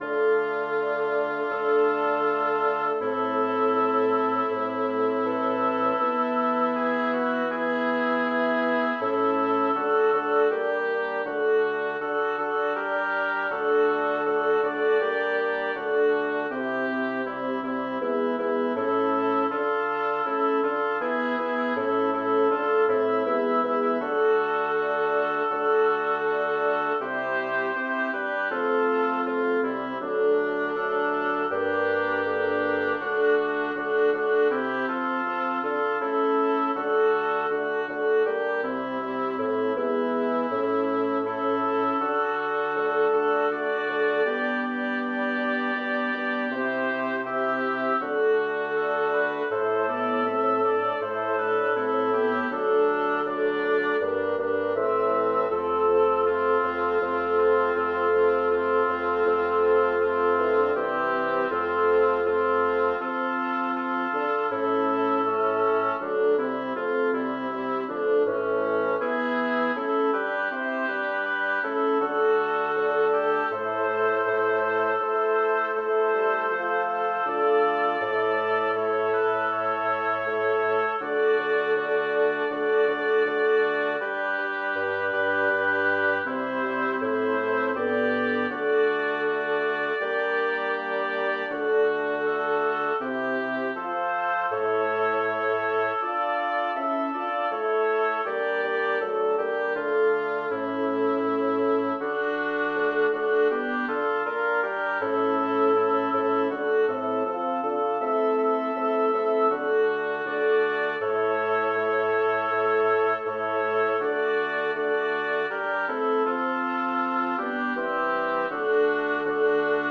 Title: Ecce petit Catharina Composer: Jacob Meiland Lyricist: Number of voices: 5vv Voicing: SATTB Genre: Secular, Madrigal
Language: Latin Instruments: A cappella